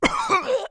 1 channel
cough1.mp3